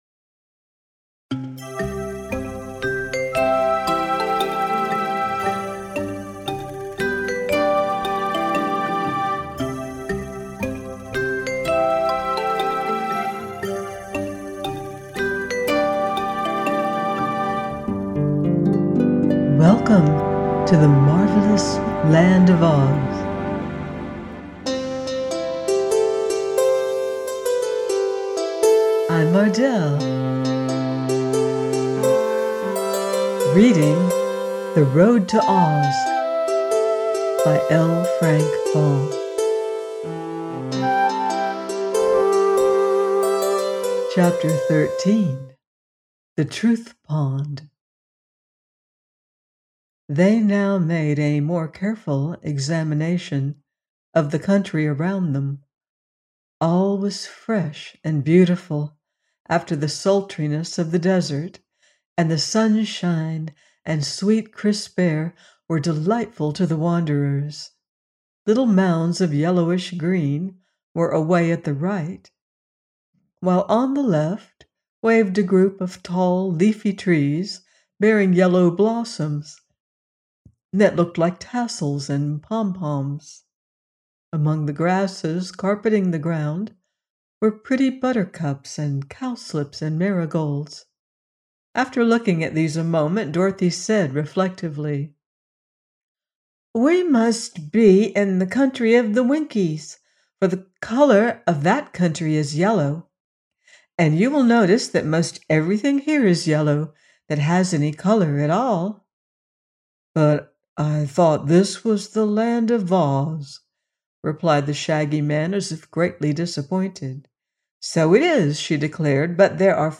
The Road To OZ – by L. Frank Baum - audiobook